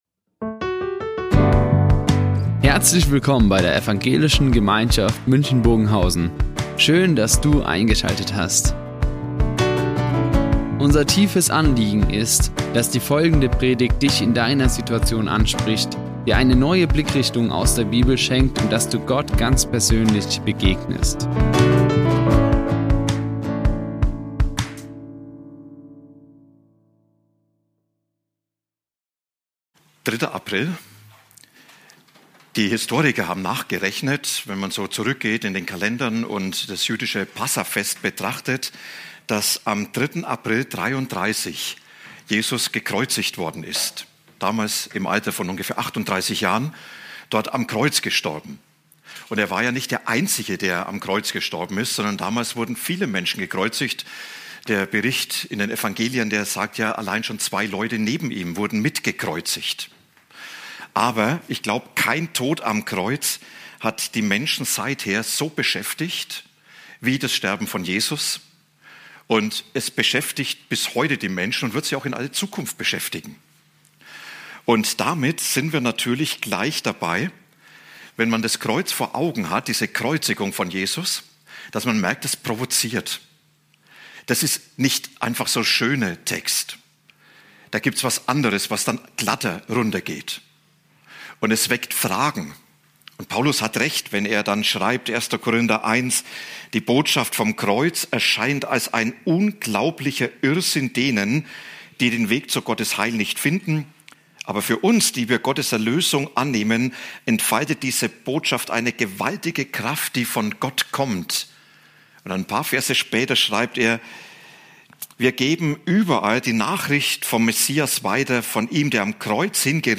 Eine Predigt